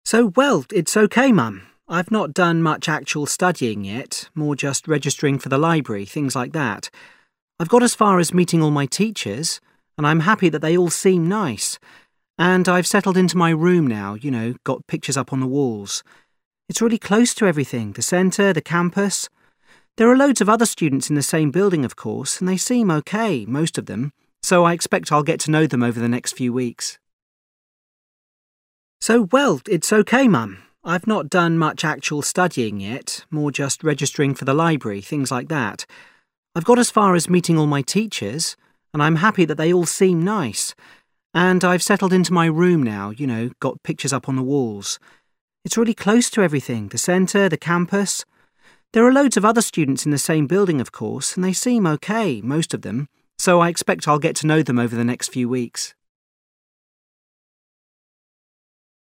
2 You overhear a student talking on the phone. What does he say about life at college?